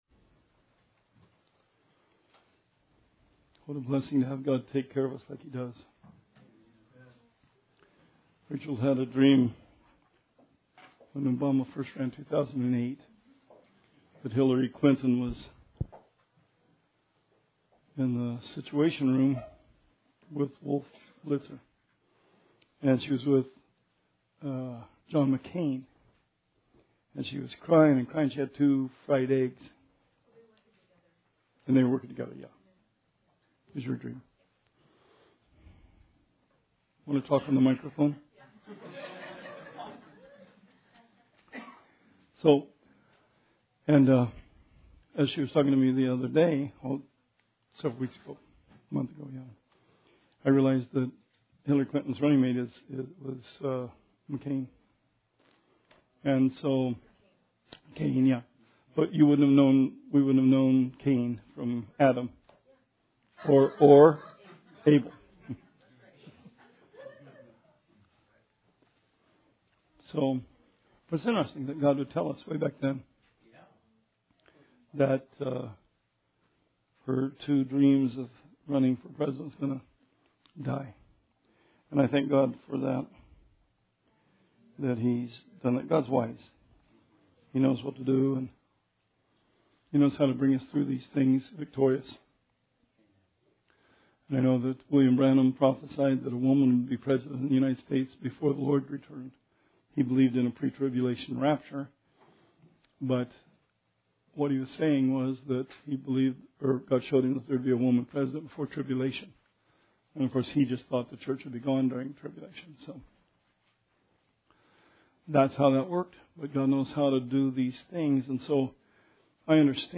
Bible Study 11/9/16